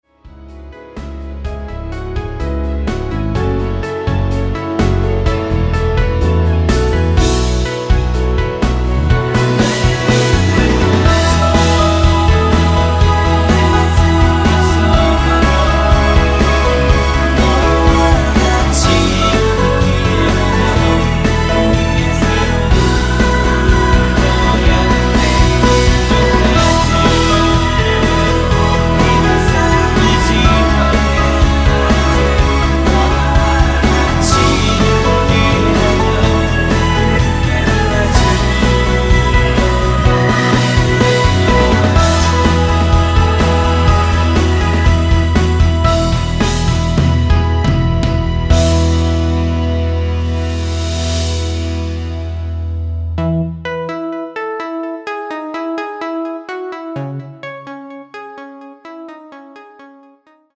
원키 끝부분 코러스 포함된 MR 입니다.(미리듣기 참조)
Eb
코러스 있는게 마음에 드네요^^
앞부분30초, 뒷부분30초씩 편집해서 올려 드리고 있습니다.